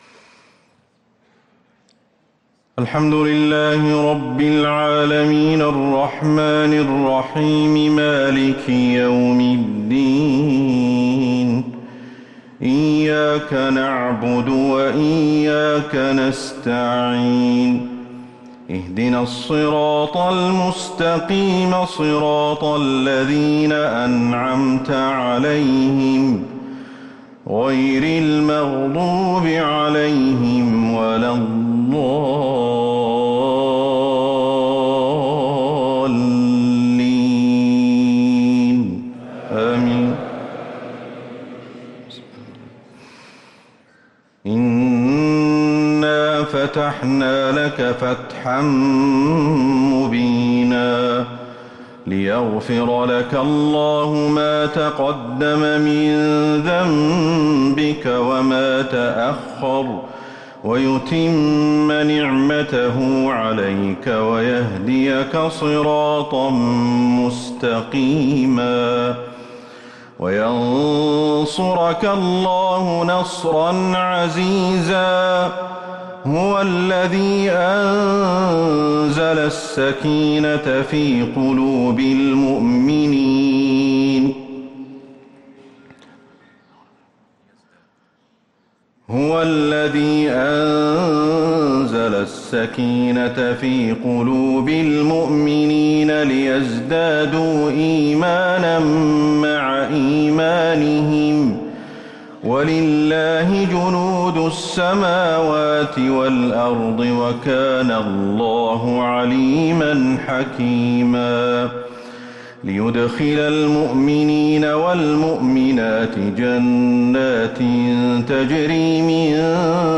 عشاء الخميس 3-8-1444هـ فواتح سورة الفتح > 1444هـ > الفروض